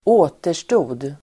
Uttal: [²'å:ter_sto:d]